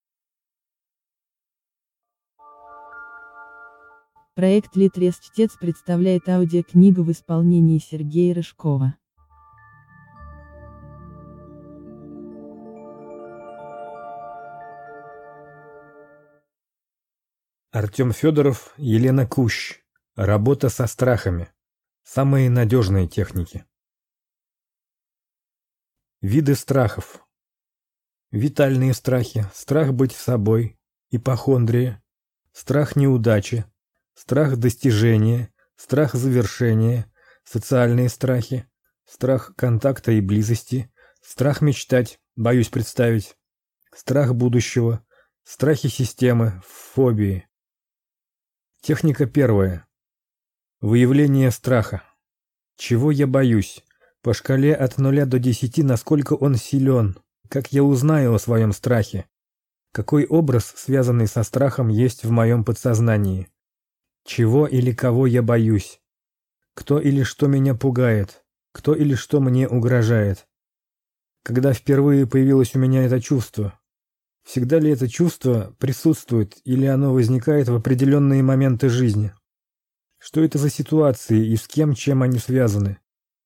Аудиокнига Работа со страхами. Самые надежные техники | Библиотека аудиокниг